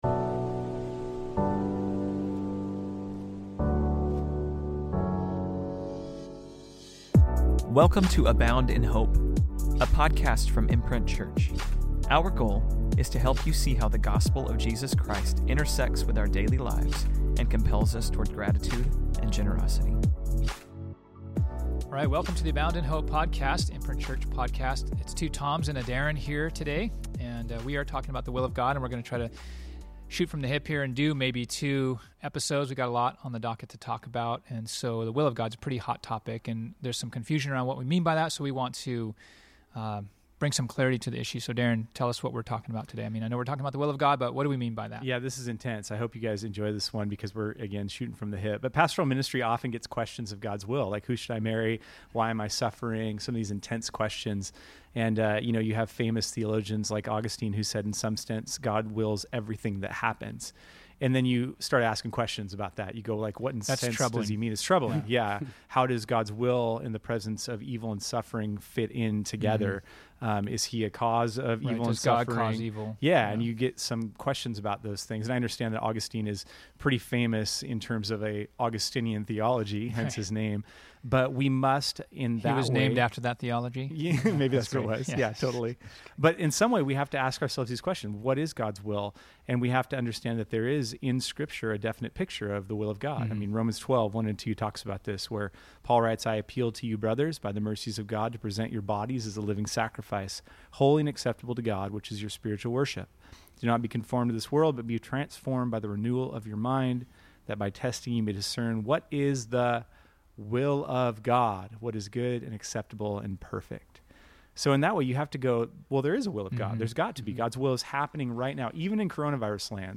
Listen in on the first half of a 2 part discussion on the will of God.